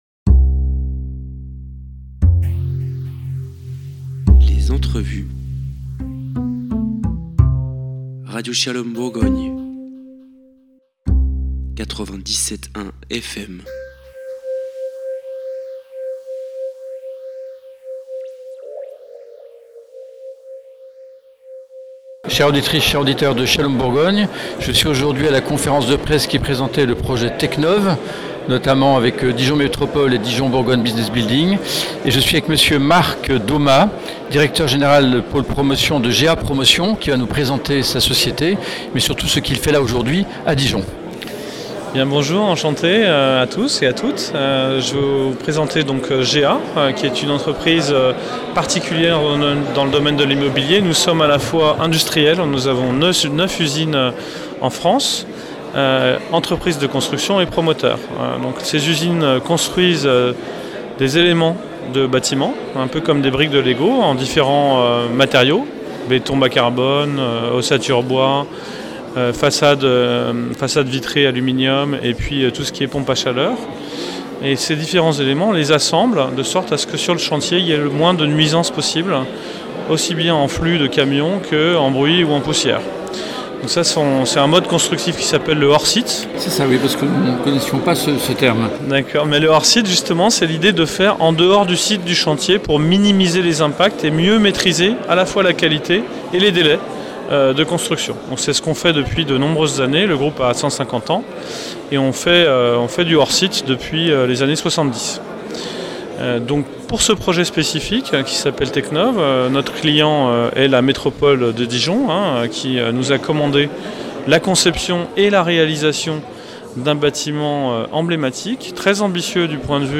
Reportage et interview